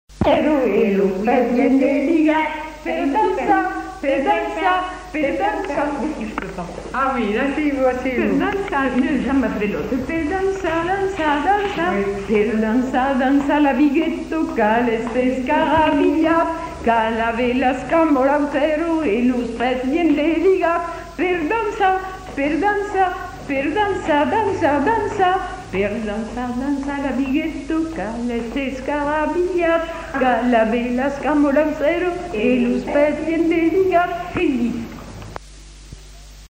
Lieu : Cancon
Genre : chant
Effectif : 1
Type de voix : voix de femme
Production du son : chanté
Danse : bigue-biguette